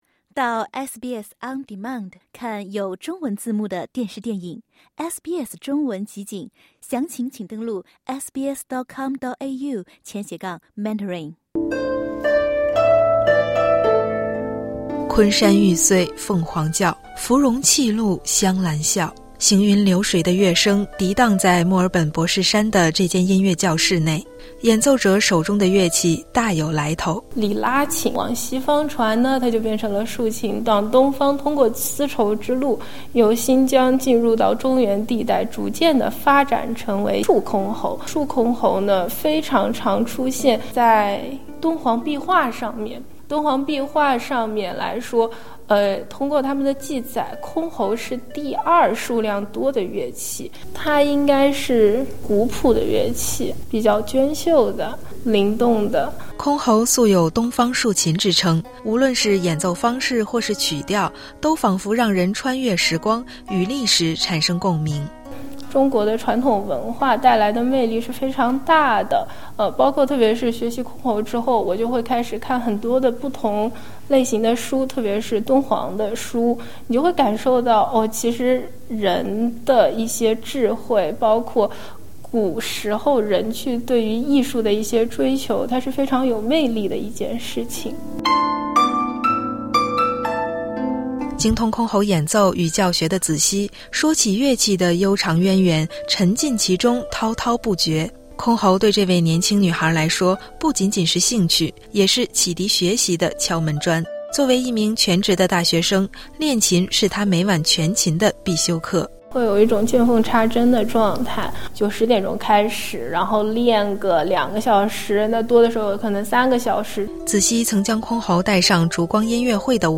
行云流水的乐声回荡在墨尔本博士山的一间音乐教室。